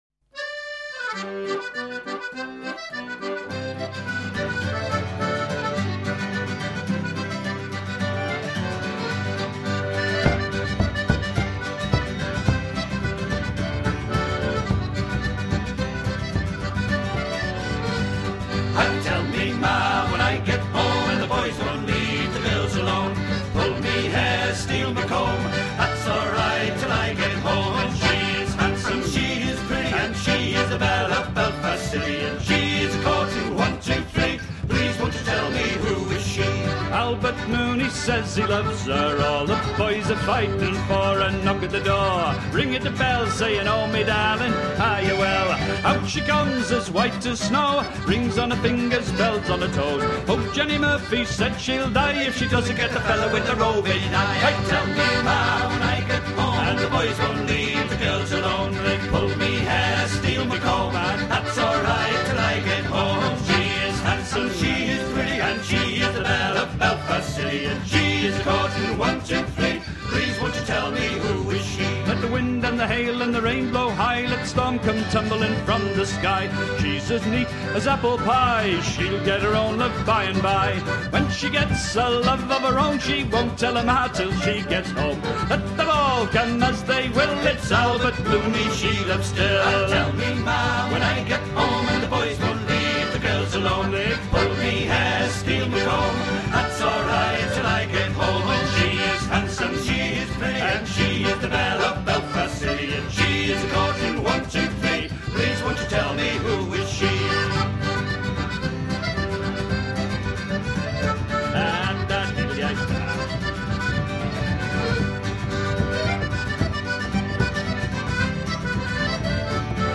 The photo shows local group Rum, Bum and Concertina.